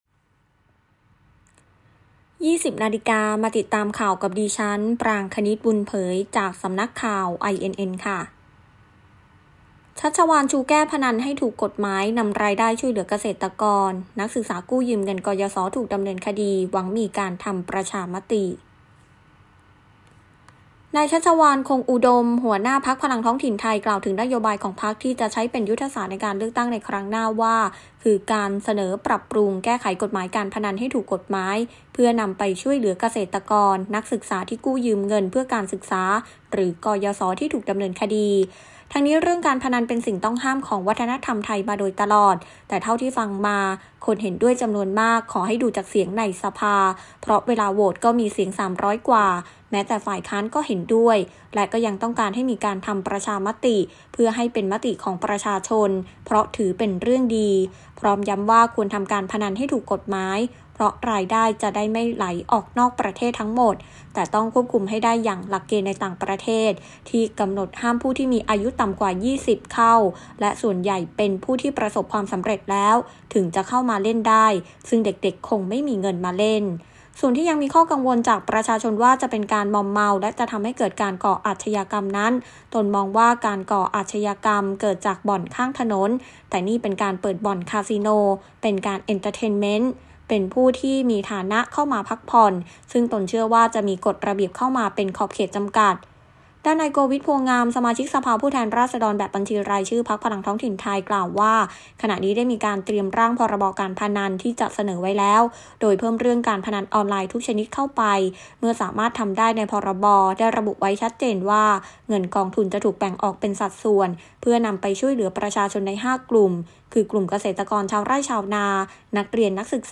คลิปข่าวต้นชั่วโมง
ข่าวต้นชั่วโมง 20.00 น.